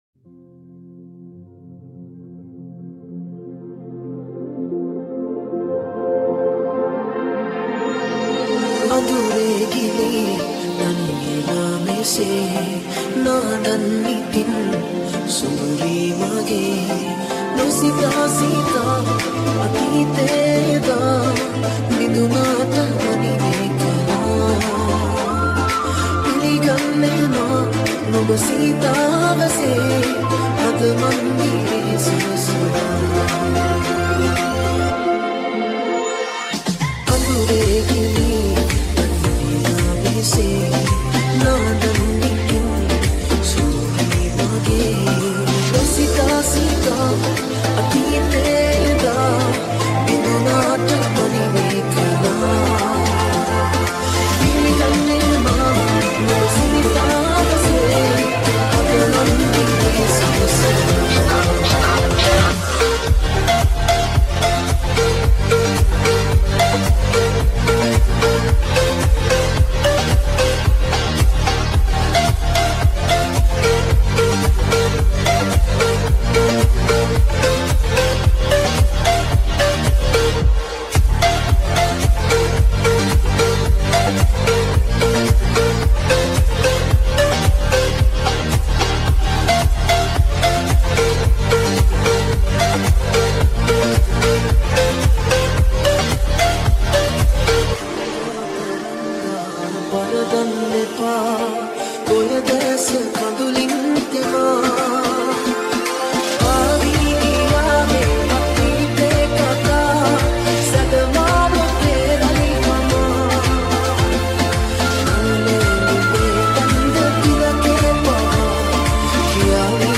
Tropical House Remix